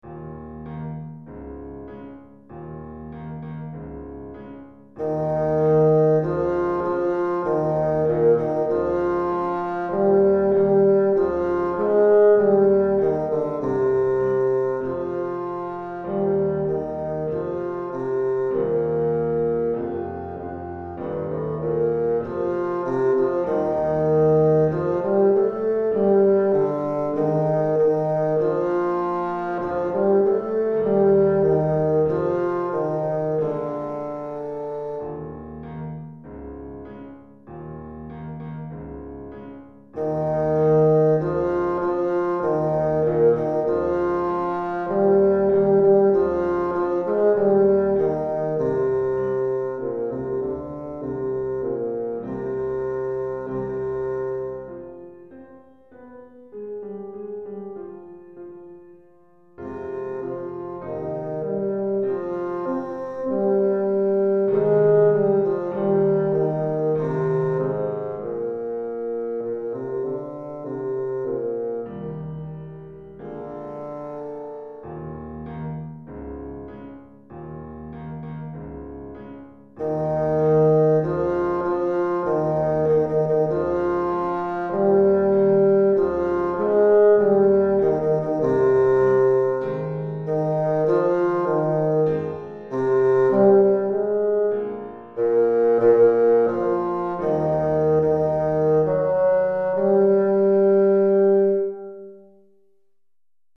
pour basson et piano DEGRE CYCLE 1 Durée
Basson et piano